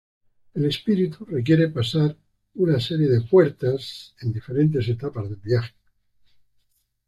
Read more (masculine) spirit, soul (masculine) mind Frequency B1 Hyphenated as es‧pí‧ri‧tu Pronounced as (IPA) /esˈpiɾitu/ Etymology Borrowed from Latin spiritus Cognate with English spirit In summary Borrowed from Latin spiritus.